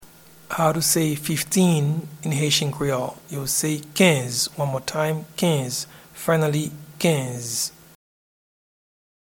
Pronunciation and Transcript:
Fifteen-in-Haitian-Creole-–-Kenz.mp3